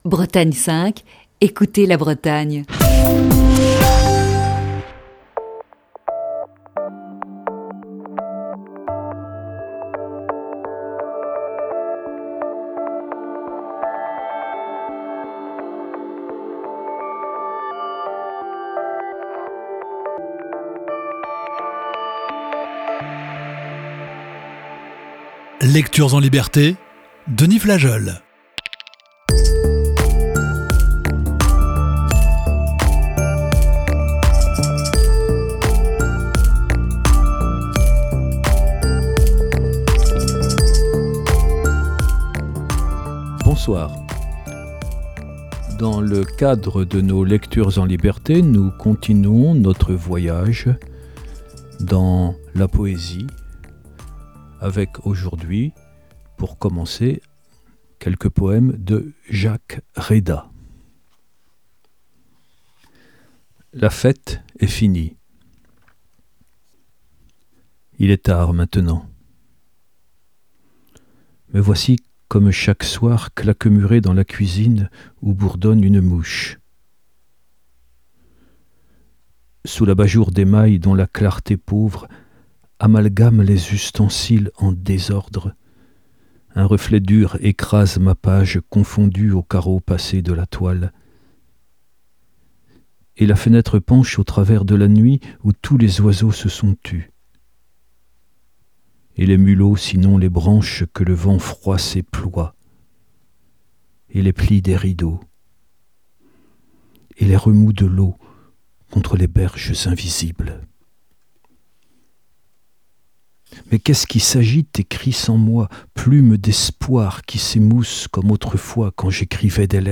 Ce soir, il nous propose la lecture de plusieurs textes et poèmes.